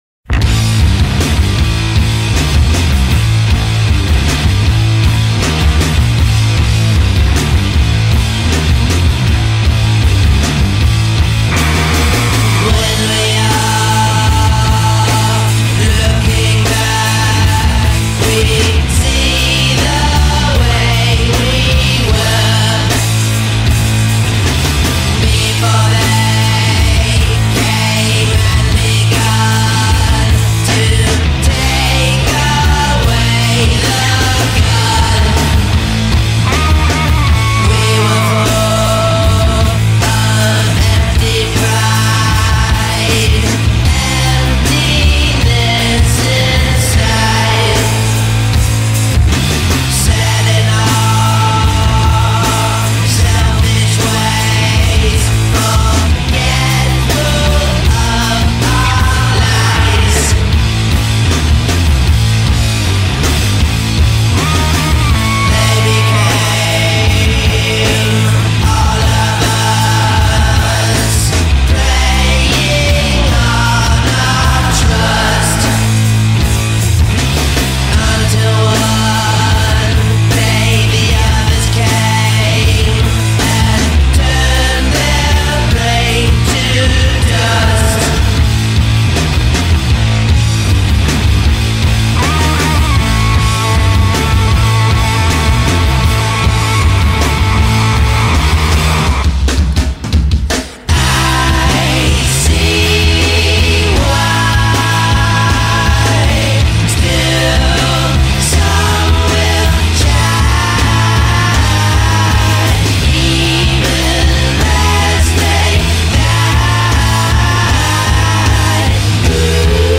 guitarist
bassist
face-smashing garage riffs
it's how thunderous its low end is.
which only underscores their distortion-drenched prowess.